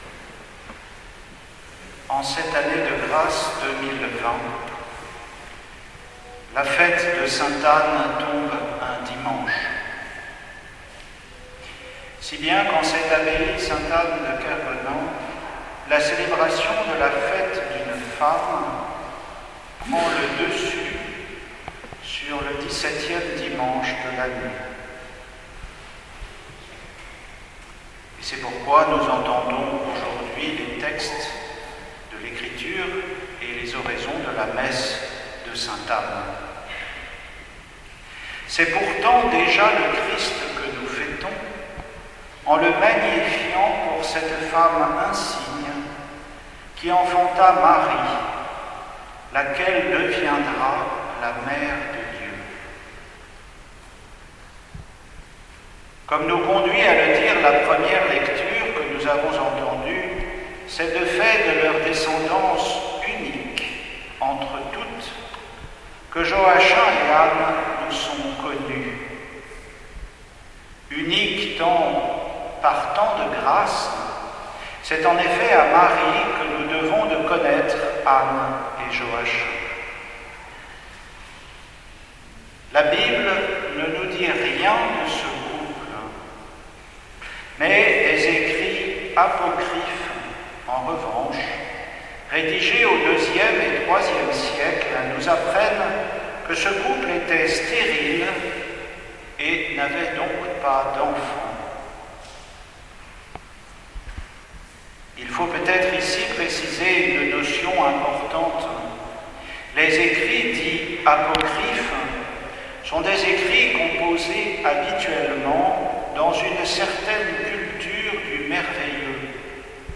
Sainte Anne 2020, homélie
Homélie pour la solennité de Sainte Anne, 26 juillet 2020